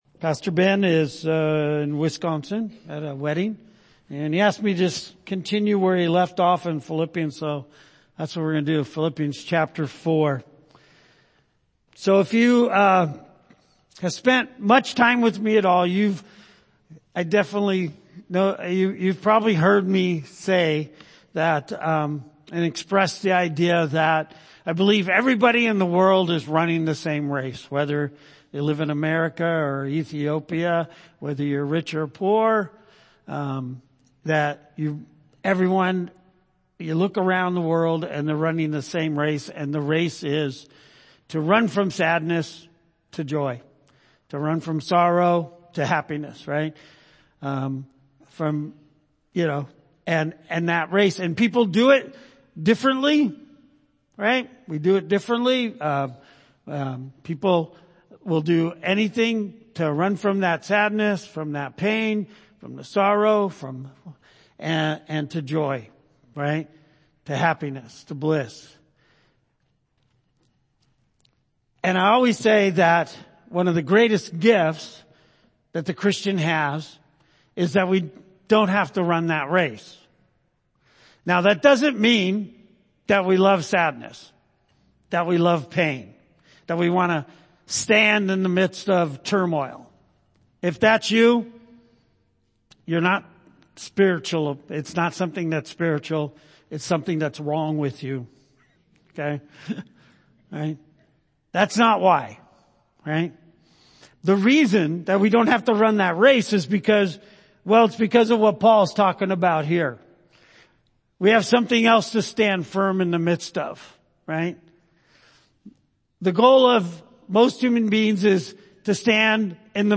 Philippians Passage: Philippians 4:1-8 Service Type: Sunday Morning « Discipleship